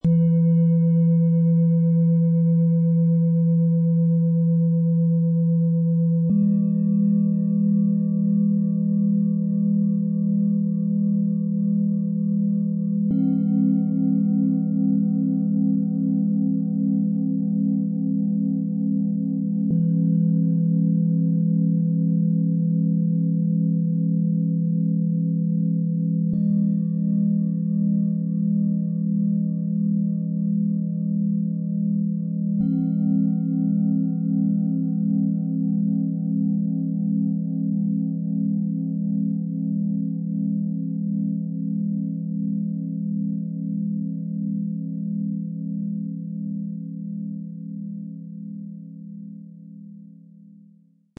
Stabilität & Klarheit im Raum - 3 Klangschalen für Meditation & Gruppenarbeit im Sound-Spirit Shop | Seit 1993
Die drei eher dickwandigen Klangschalen erzeugen stehende, gut tragende Töne, die Struktur geben, sammeln und einen ruhigen Klangraum öffnen.
Der Klang dieses Sets wirkt beruhigend, stabilisierend und ordnend.
Die Schalen bauen keinen starken Vibrationsdruck auf, sondern schaffen eine klare, verlässliche Präsenz im Raum.
Ihr Ton ist ruhig, stabilisierend und zentrierend und schafft eine verlässliche Basis für den gesamten Klangraum.
Die mittlere Schale bringt einen freundlichen, freudigen Klangcharakter ein.
Die kleine Schale besitzt eine besondere Qualität: Je nach Spielweise lassen sich sowohl ein tragender, tieferer Ton als auch ein klar stehender, höherer Ton entfalten.
Dank unseres Sound-Player - Jetzt reinhörens können Sie den echten, unverfälschten Klang genau dieser drei Klangschalen in Ruhe selbst anhören.
So erhalten Sie einen guten Eindruck von der klaren, stehenden und stabilen Klangqualität, die dieses Set besonders für Raum- und Gruppenarbeit auszeichnet.
MaterialBronze